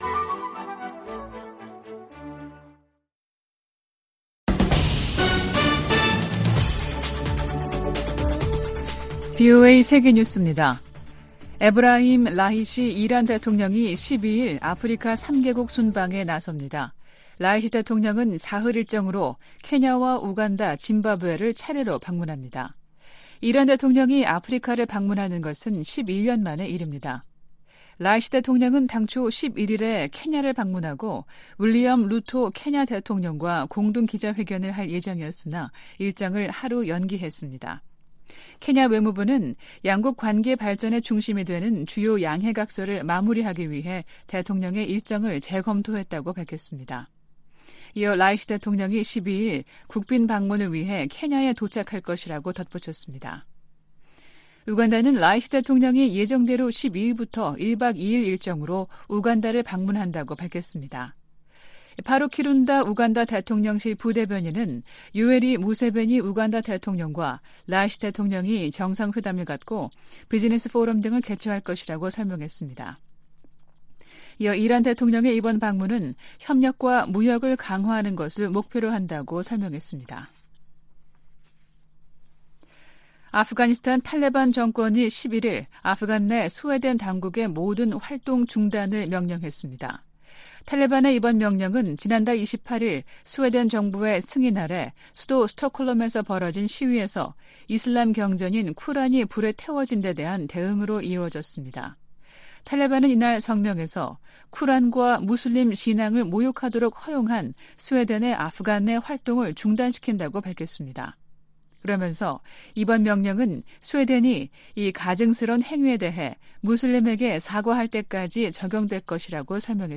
VOA 한국어 아침 뉴스 프로그램 '워싱턴 뉴스 광장' 2023년 7월 12일 방송입니다. 북한 김여정 노동당 부부장이 이틀 연속 미군 정찰기의 자국 상공 침범을 주장하며 군사적 대응을 시사하는 담화를 발표했습니다. 이와 관련해 미 국무부는 북한에 긴장 고조 행동 자제를 촉구했습니다. 미한 핵 협의그룹 NCG 첫 회의가 다음 주 서울에서 열립니다.